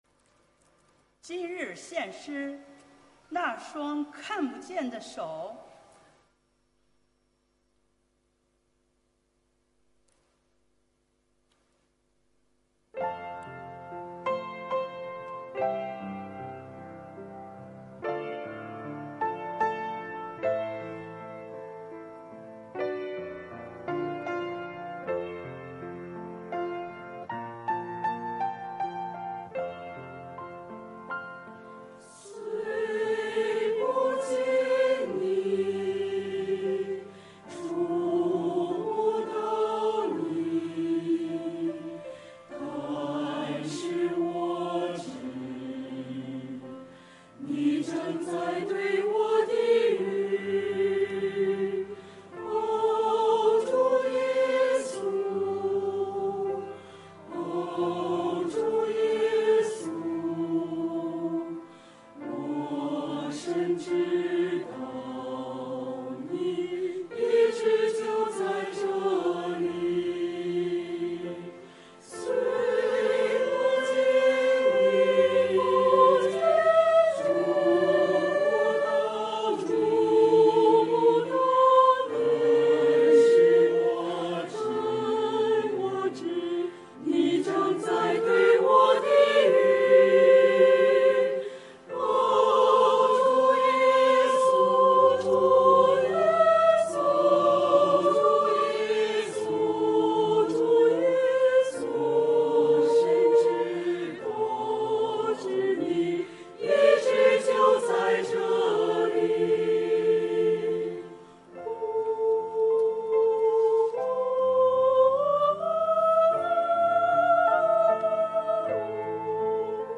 团契名称: 联合诗班 新闻分类: 诗班献诗 音频: 下载证道音频 (如果无法下载请右键点击链接选择"另存为") 视频: 下载此视频 (如果无法下载请右键点击链接选择"另存为")